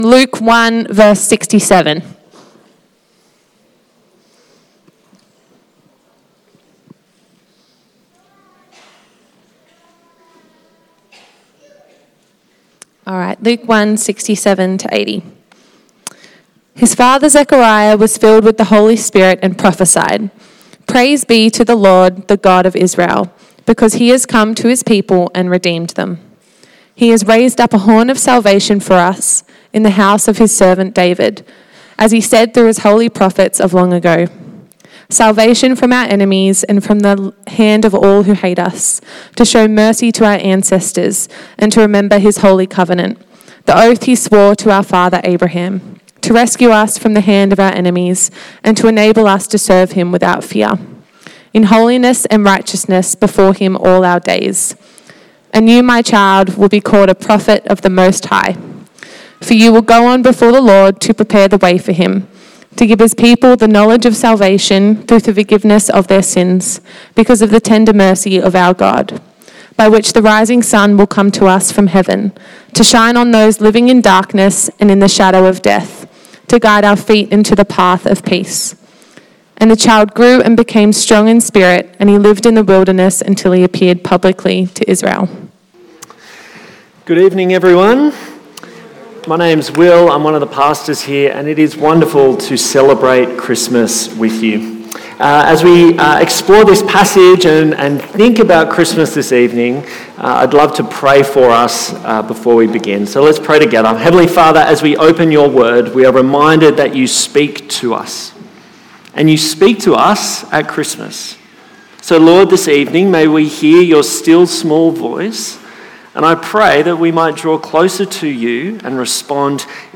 Luke 1:67-80 Service Type: PM  Joi  n us on Christmas Eve as we reflect on Zechariah's Prophecy and the joy he experienced.